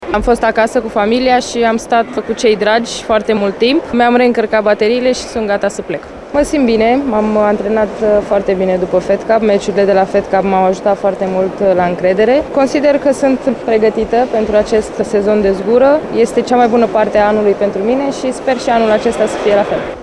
01mai-12-Voce-Halep-gata-de-lupta.mp3